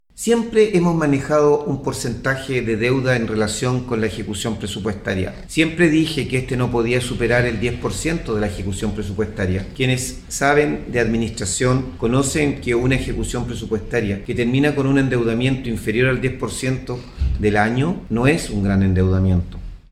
02-ALCALDE-MELLA-Endeudamiento.mp3